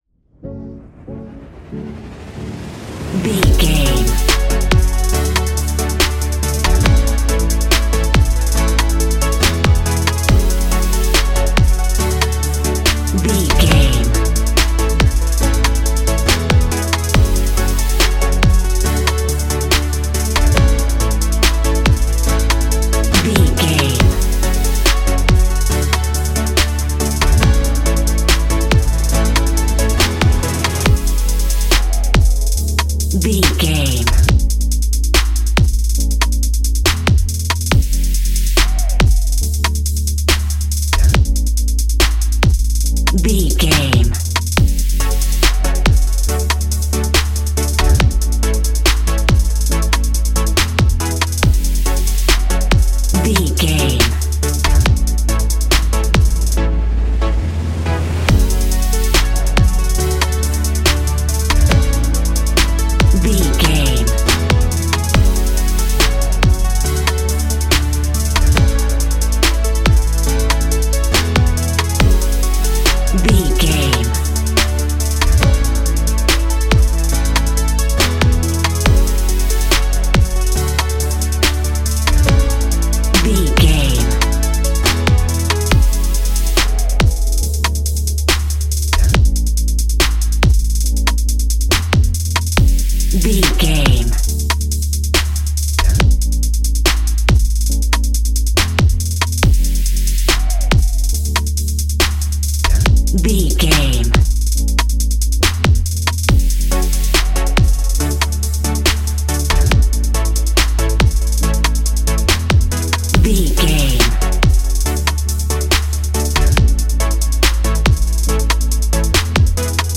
Ionian/Major
D♭
ambient
electronic
new age
chill out
downtempo
synth
pads